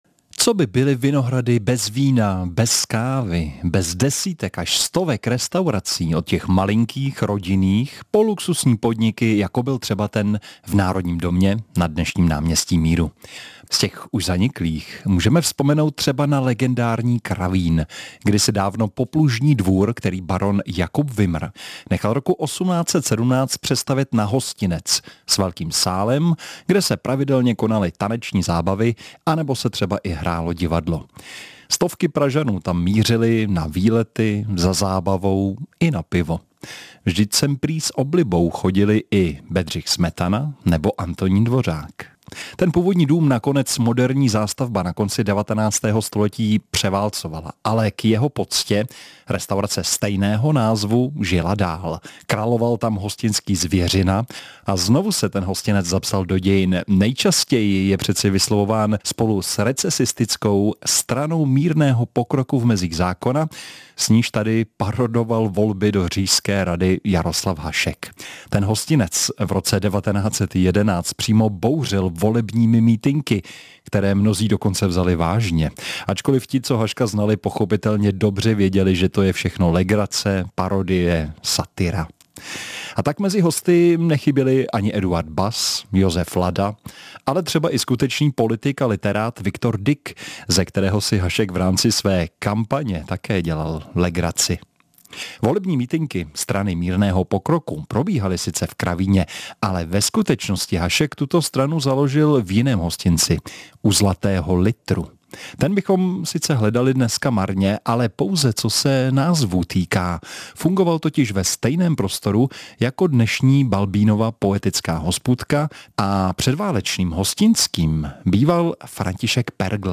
povídáním o vinohradských hostincích